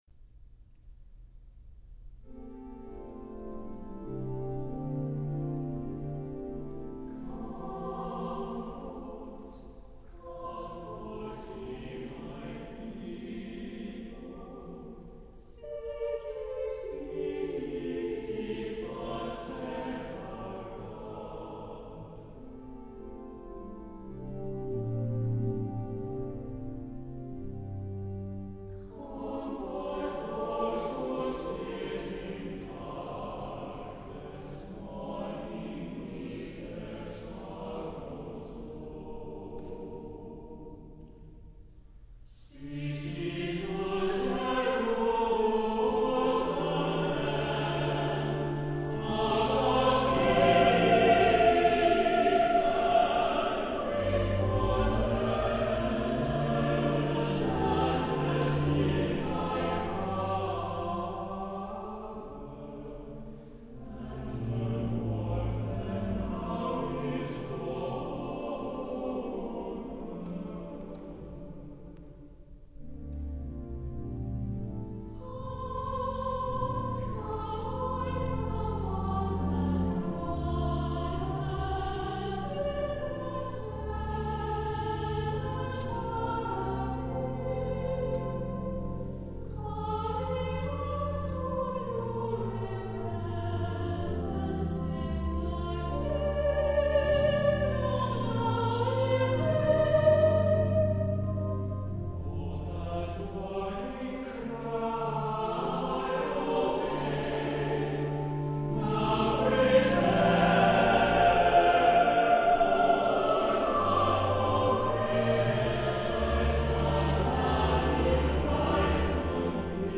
• for Advent or general use, set in a gentle, lyric style
• four-part harmony with a chant-like melodic flow